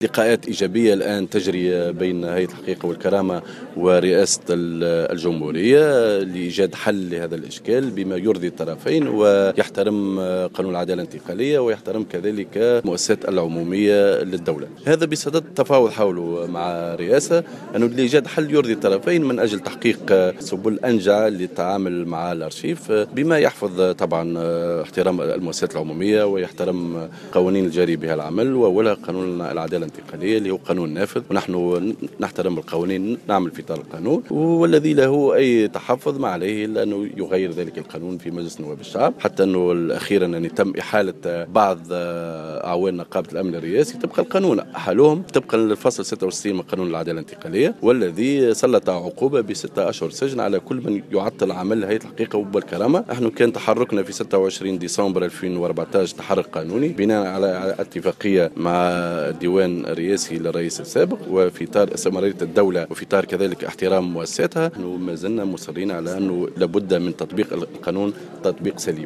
Le vice président de l’instance vérité et dignité Khaled Krichi a déclaré ce mercredi 28 janvier lors d’une conférence de presse que l’instance négocie actuellement avec le président de la République Beji Caied Essebsi dans le but de parvenir à une solution concernant l’archive de la présidence.